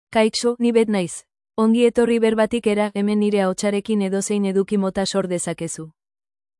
FemaleBasque (Spain)
Beth is a female AI voice for Basque (Spain).
Voice sample
Female